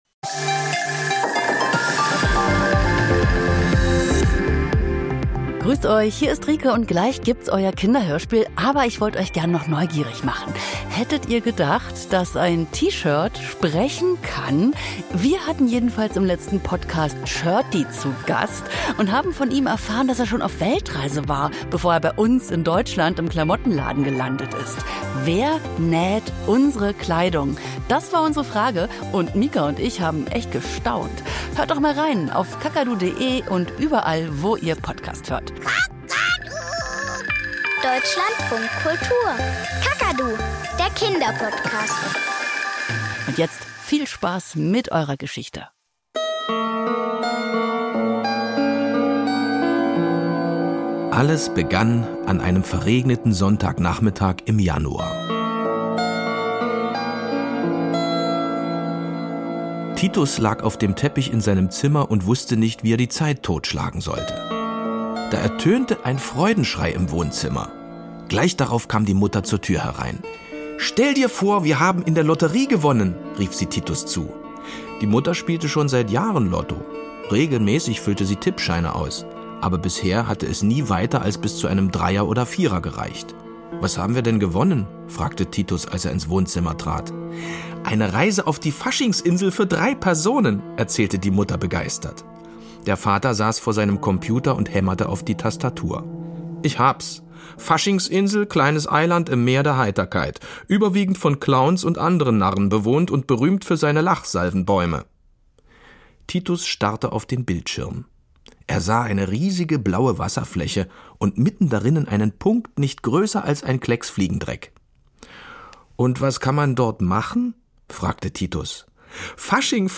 Kinderhörspiel und Geschichten - Die Faschingsinsel